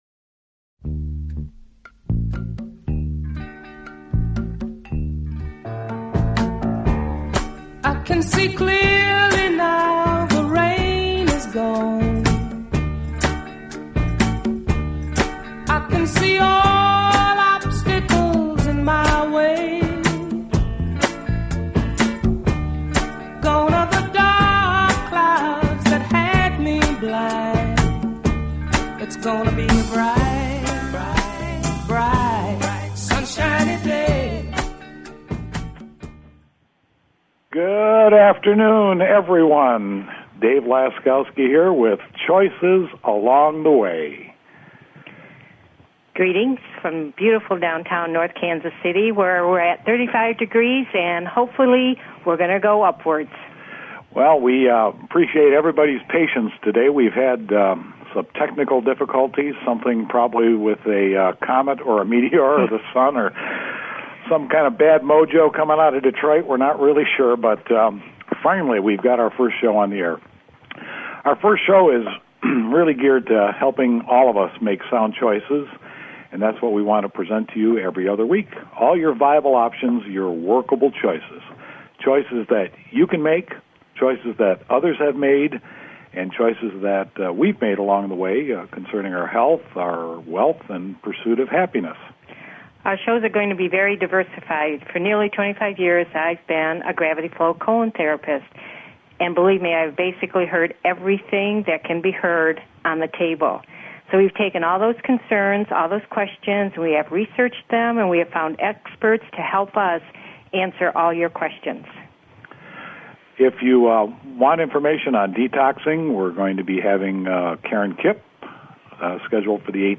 Talk Show Episode, Audio Podcast, Choices_Along_The_Way and Courtesy of BBS Radio on , show guests , about , categorized as